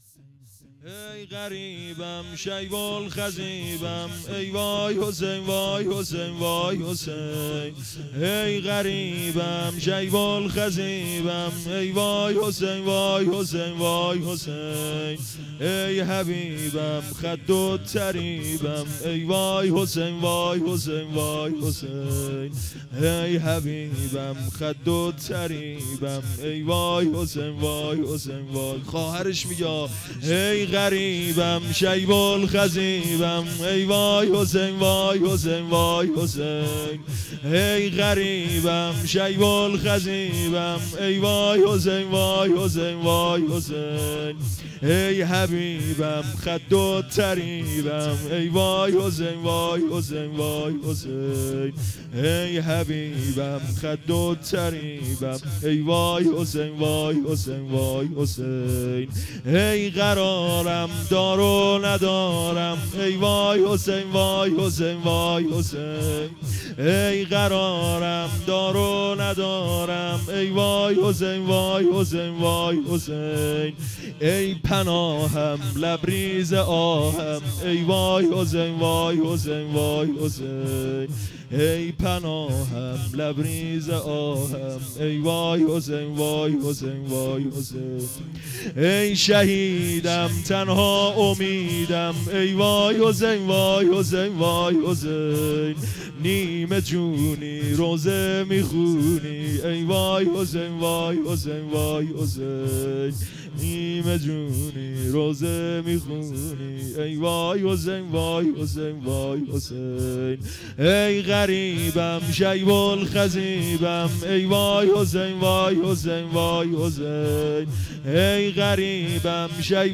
هیئت معزالمومنین(علیه‌السلام) قم
شور ای وای حسین وای حسین